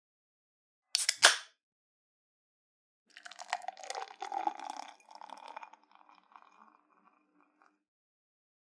openCanSound.mp3